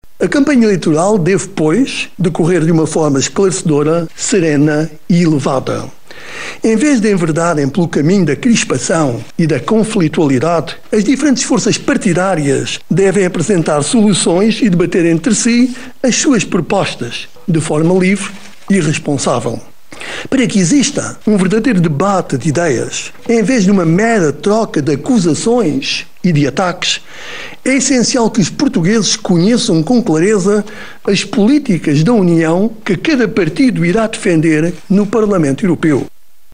O dia foi comunicado aos portugueses pelo presidente da República.